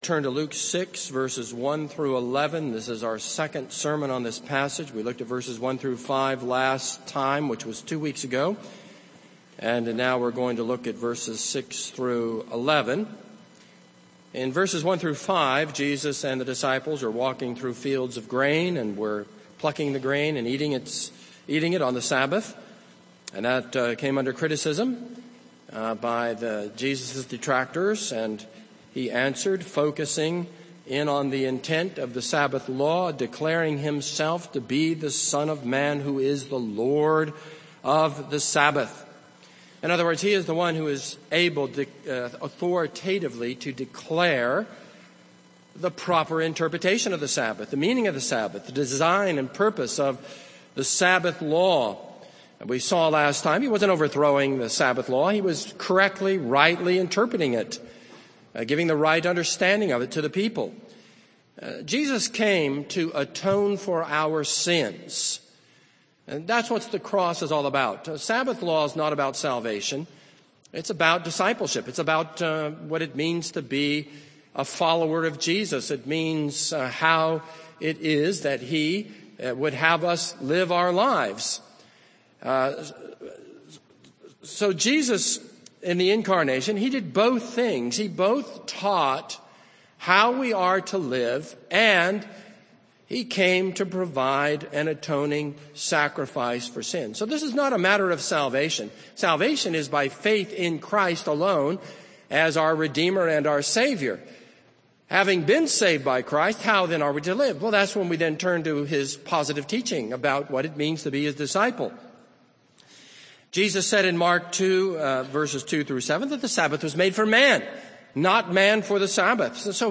This is a sermon on Luke 6:1-11.